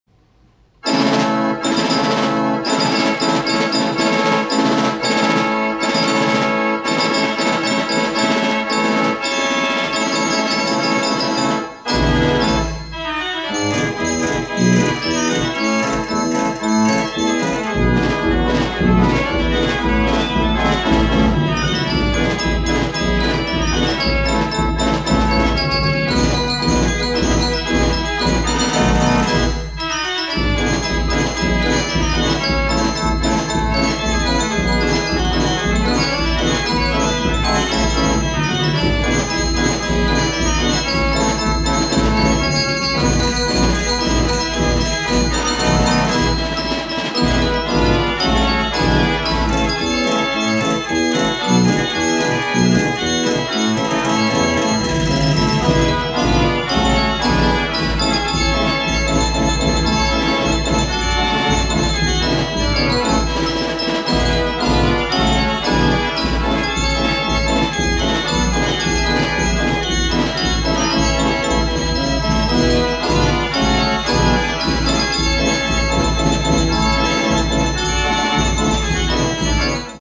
Carousel Fair Organ
her music is very exhilarating